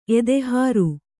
♪ edehāru